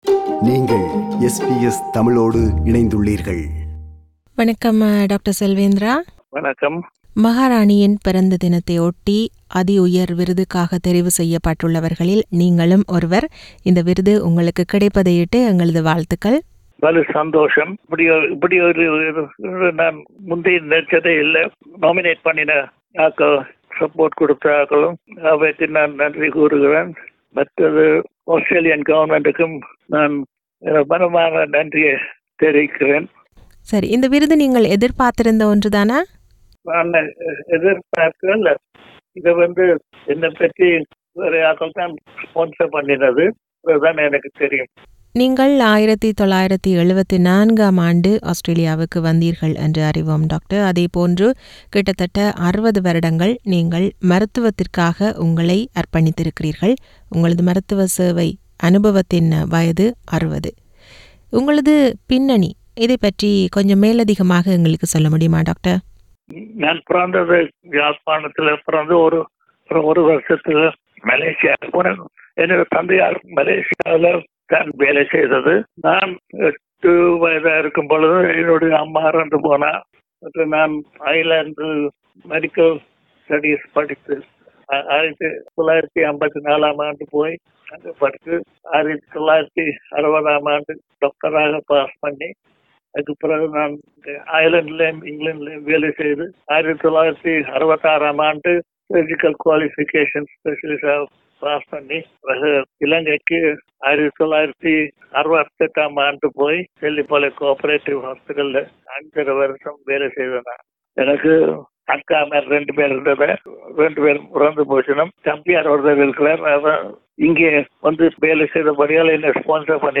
அவர்களுடனான நேர்காணல்.